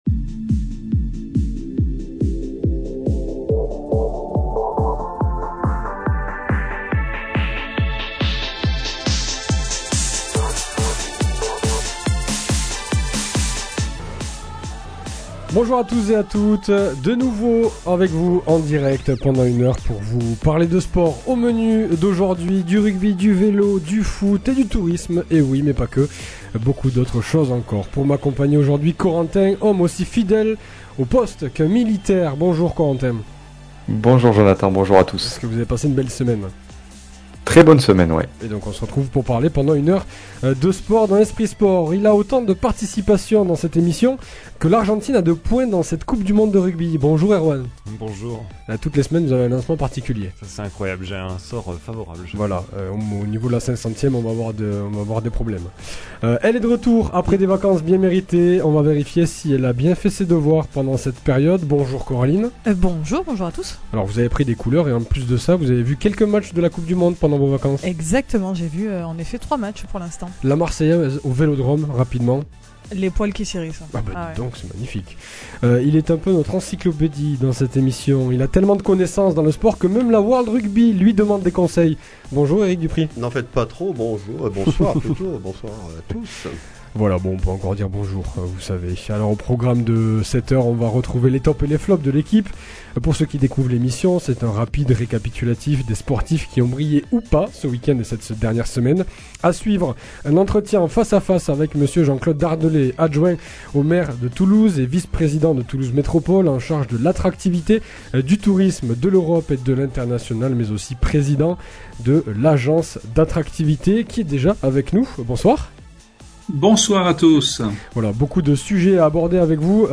L’invité, c’est Jean-Claude Dardelet, Adjoint à la Mairie de Toulouse et président d’Agence d’attractivité de Toulouse Métropole. Il nous parle des retombées touristiques et économiques de la Coupe du monde de Rugby à Toulouse.